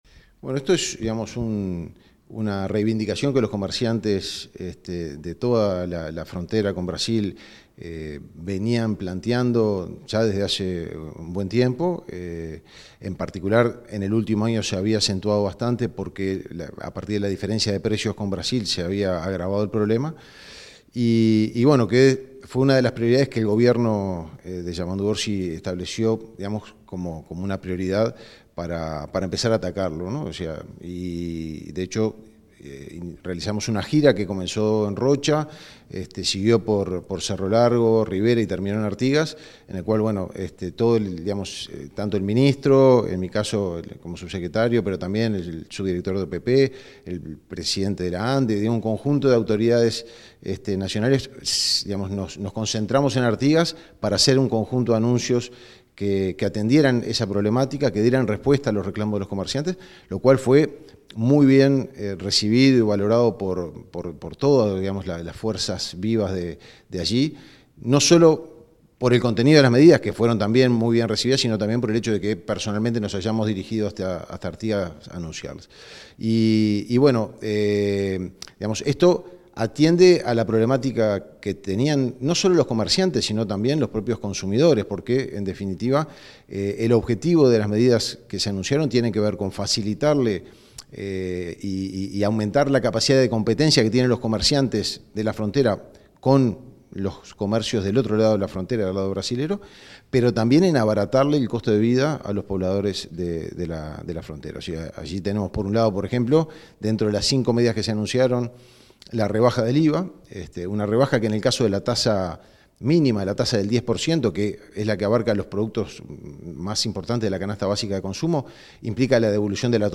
Declaraciones del subsecretario de Economía y Finanzas, Martín Vallcorba